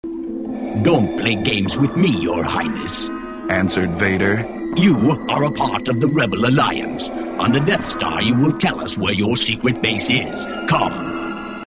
They're in mp3 format, and the quality is, uh, not excellent.
Apparently, they didn't secure the voice rights from the actors, so "voice-alikes" are used. my favorite is Darth Vader- he sounds like he's doing Dracula impressions.